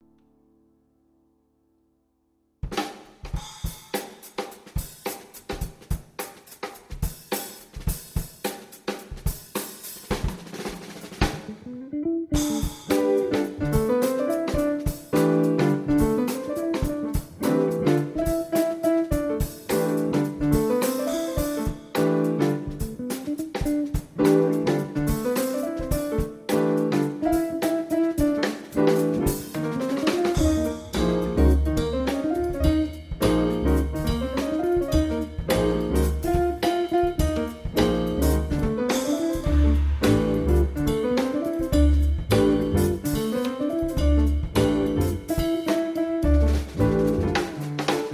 piano digitale
CD quality 48'' 479 K
My jazz 4tet